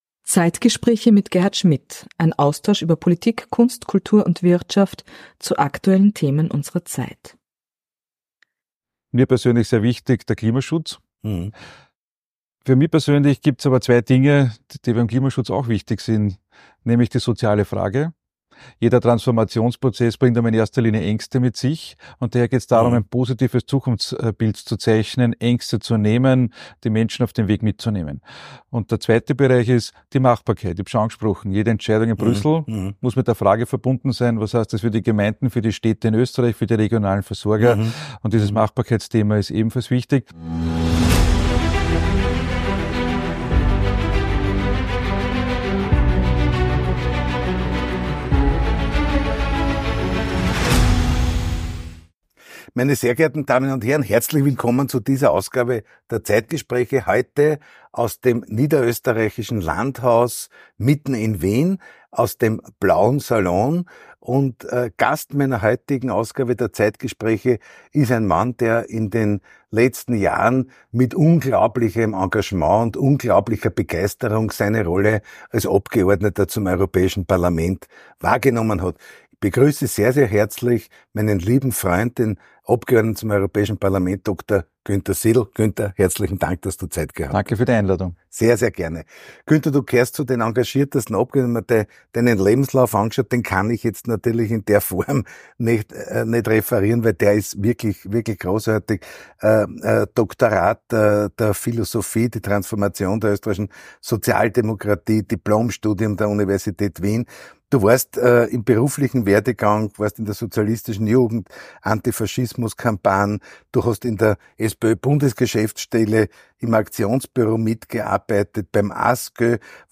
Das Gespräch suchen und finden.